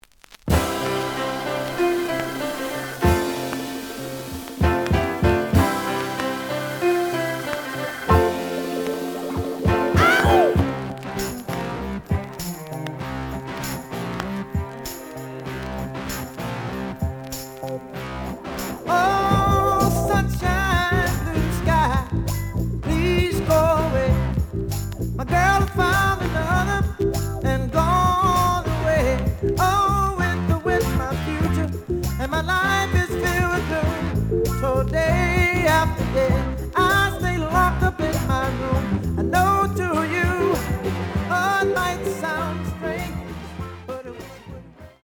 The audio sample is recorded from the actual item.
●Genre: Soul, 70's Soul
Some click noise on beginning of B side.)